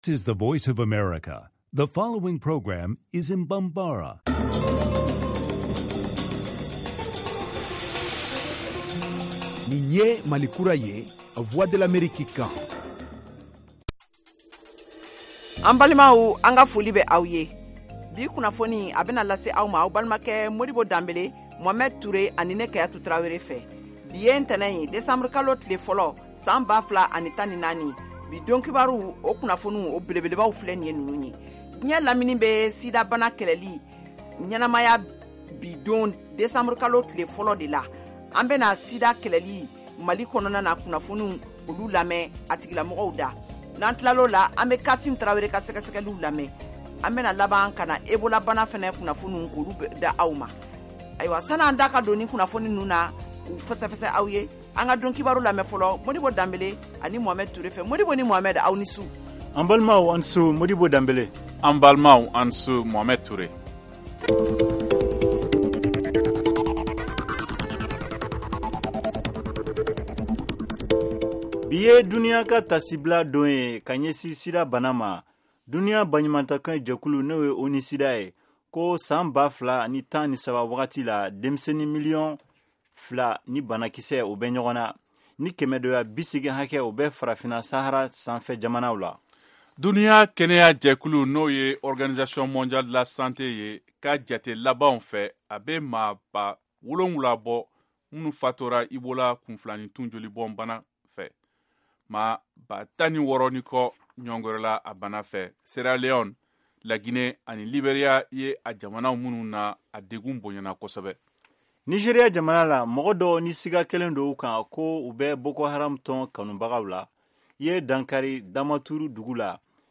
en direct de Washington, DC, aux USA